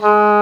WND D OBOE00.wav